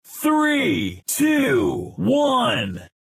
Decompte.mp3